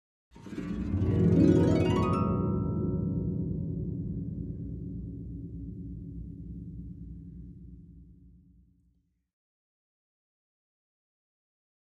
Harp, Low Strings Ascending Gliss, Type 3